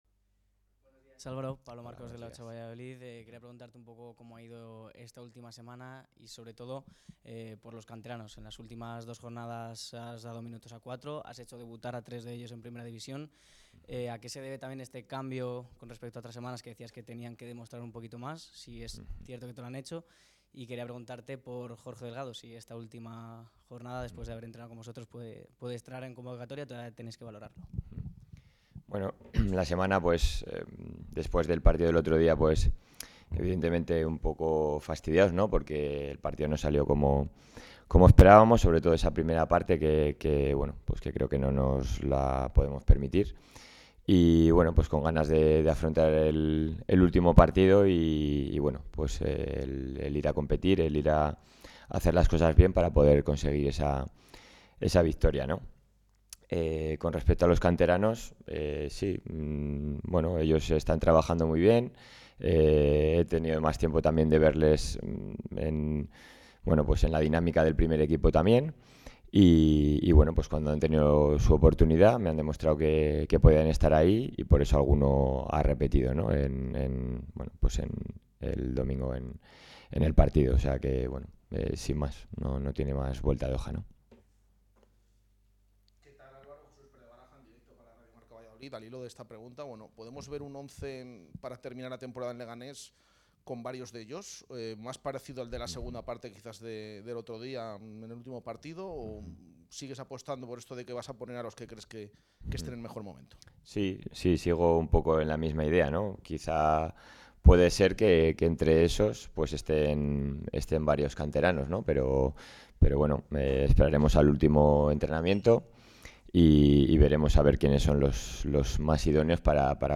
La sala de prensa del Estadio José Zorrilla acogió este jueves la última comparecencia previa de Álvaro Rubio este curso.